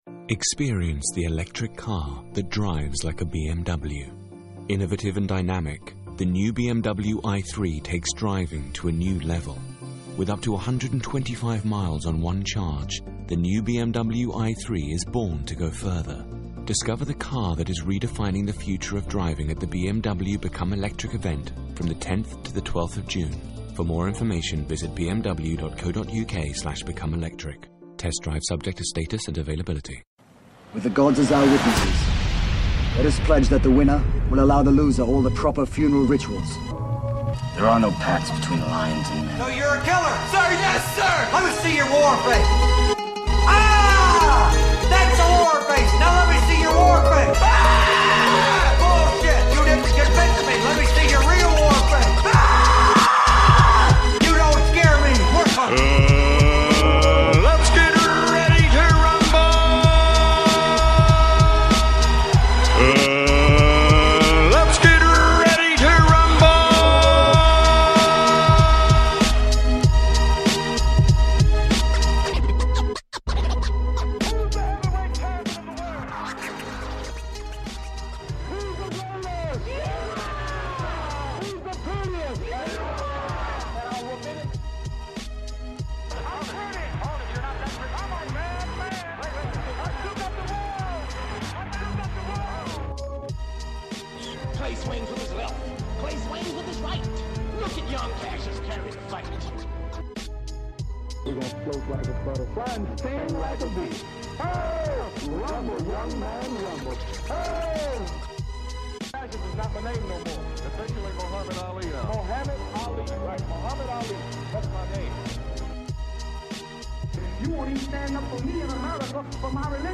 brings in-sight to up-coming fights and passes the latest news in the sport with a fast pace style of 30 minutes or less.